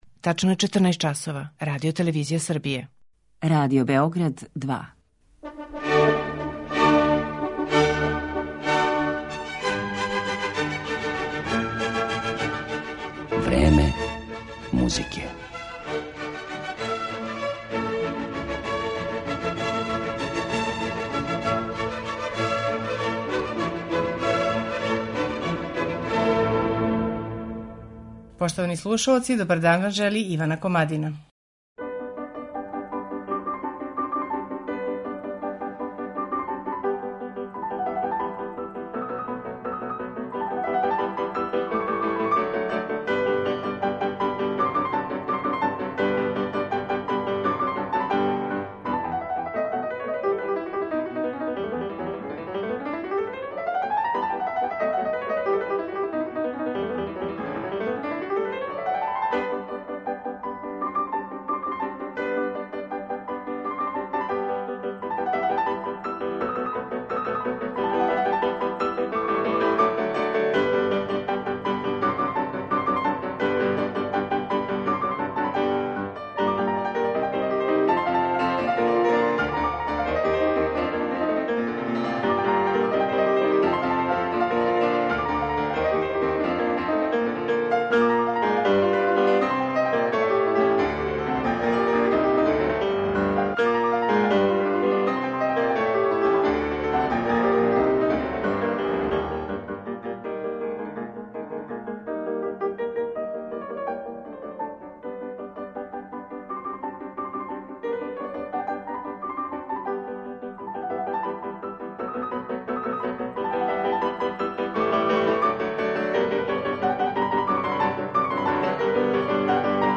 Доносимо најзанимљивије детаље из студије 'Бетовен – музика и живот' и мало познате Бетовенове композиције